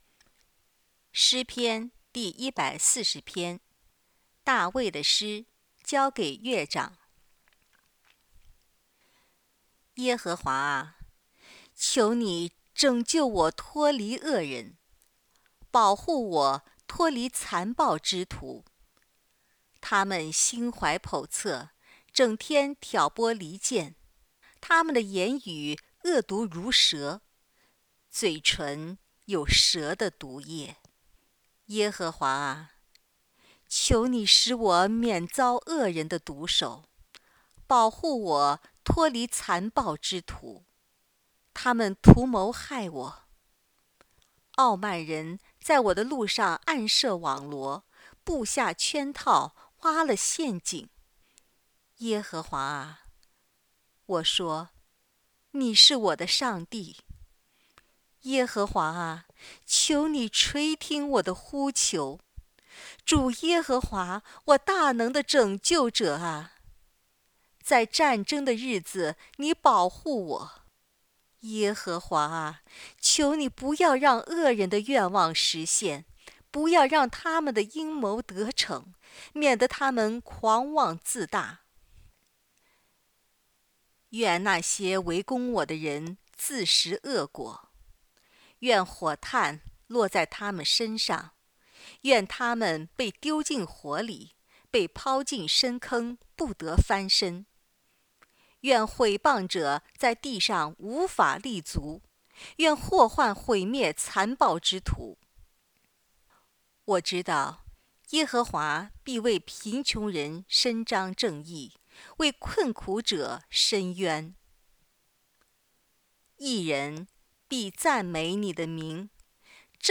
【圣经朗读】 诗篇